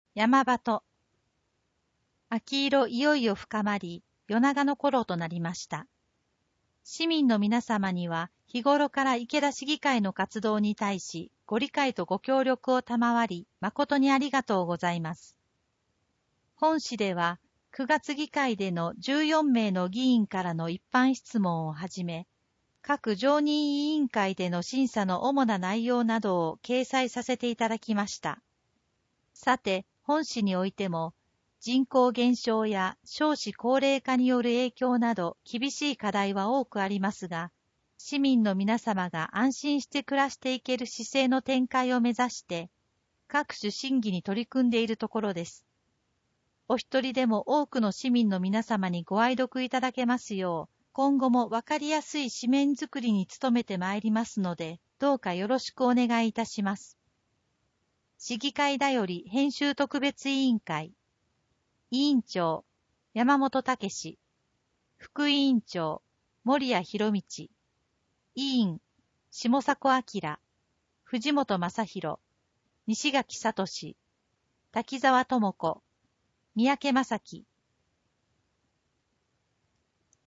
声の市議会だより